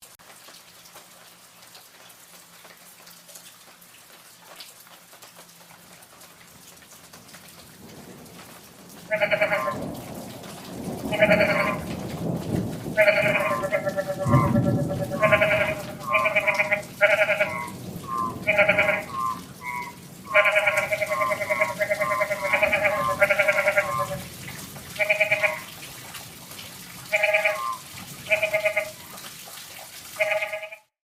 Free Nature sound effect: Toads Enjoying The Rain.
Toads Enjoying The Rain
Toads Enjoying the Rain.mp3